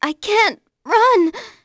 Artemia-Vox_Dead.wav